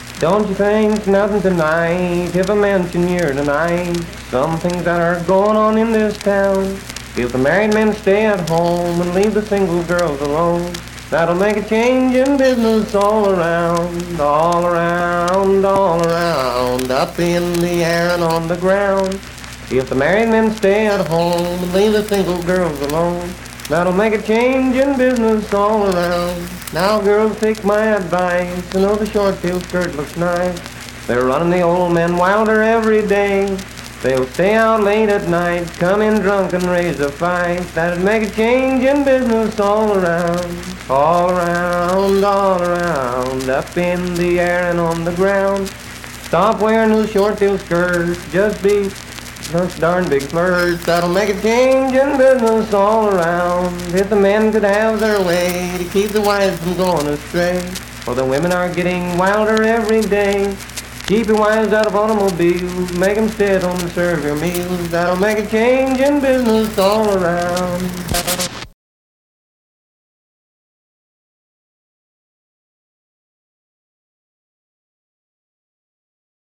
Unaccompanied vocal performance
Voice (sung)
Roane County (W. Va.), Spencer (W. Va.)